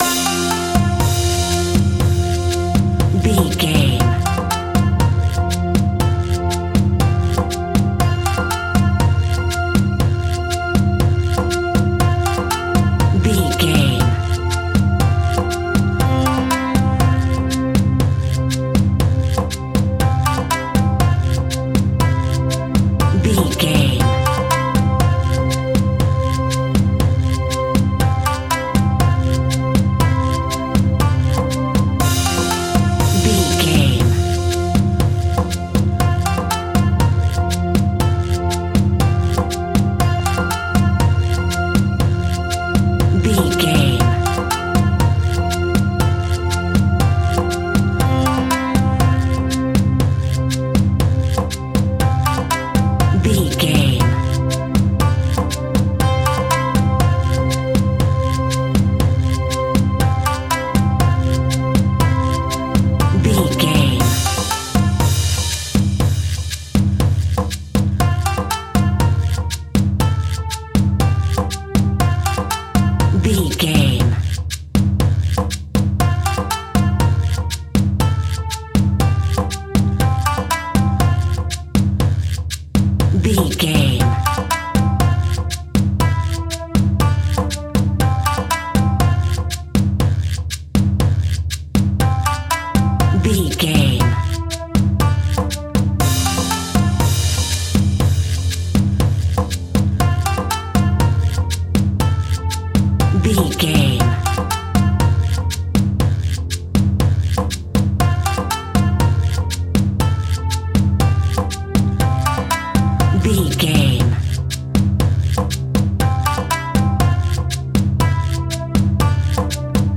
Ionian/Major
World Music
ethnic percussion
ethnic strings